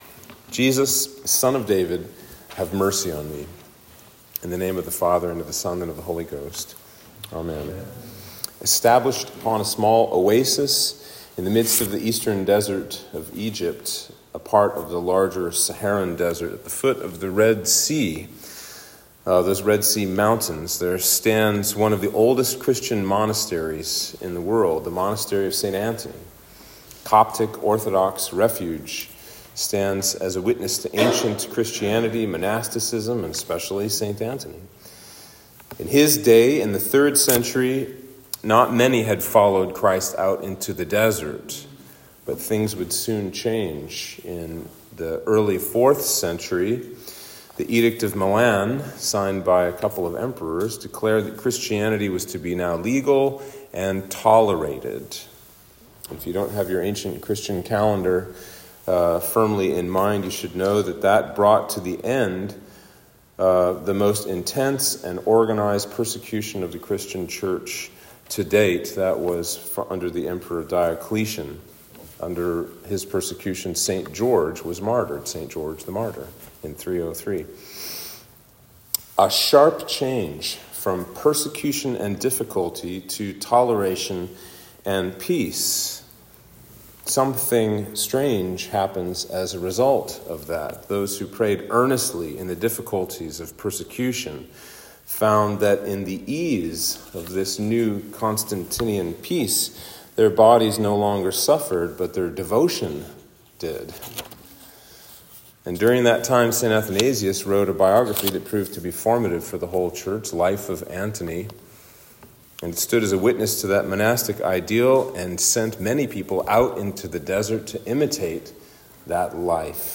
Sermon for Quinquagesima Sunday